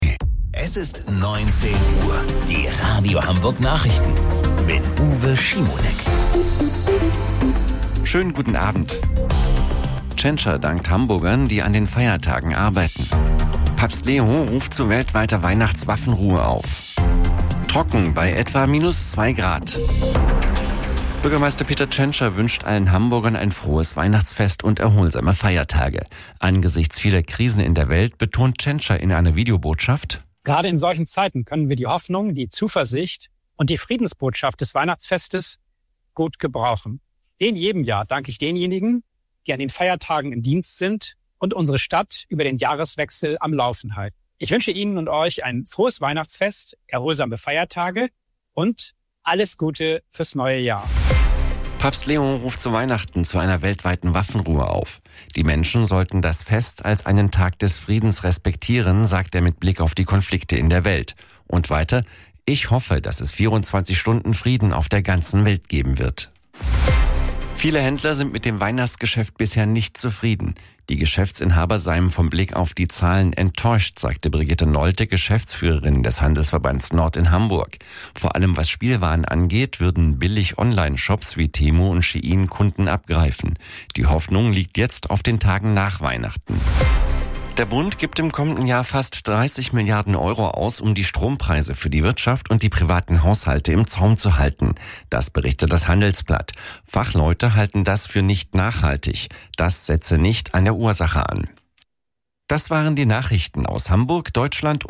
Radio Hamburg Nachrichten vom 24.12.2025 um 19 Uhr